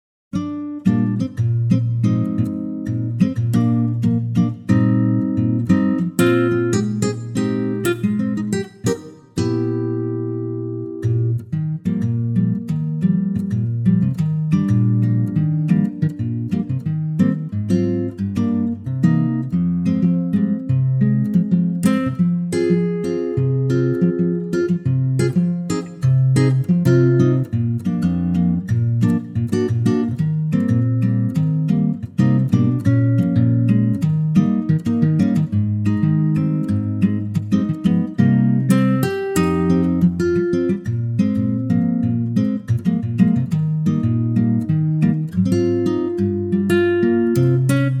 key - Eb - vocal range - G to Ab